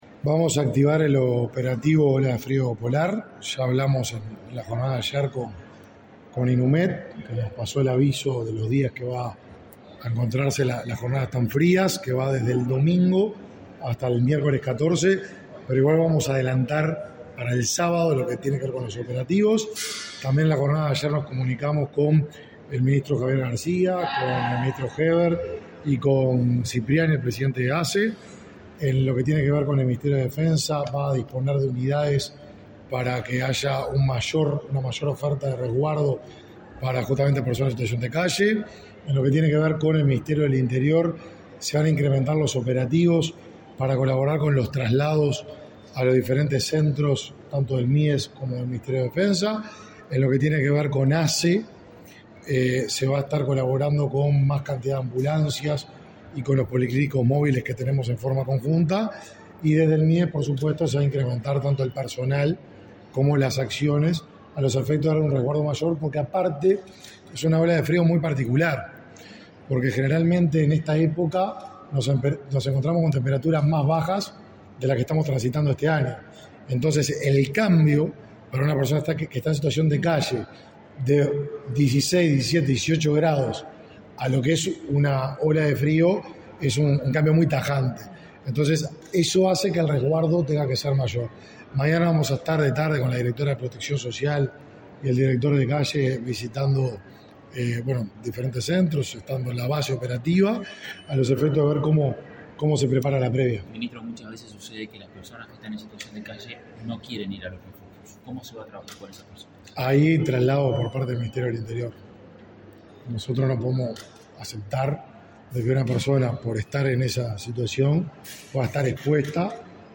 Declaraciones a la prensa del ministro del Mides, Martín Lema